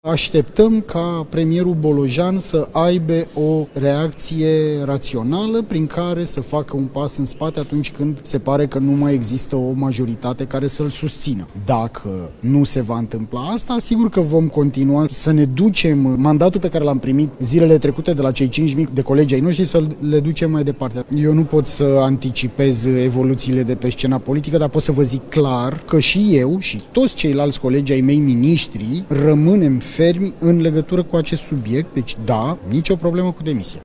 Ministrul Muncii, Florin Manole: „Rămânem fermi în legătură cu acest subiect”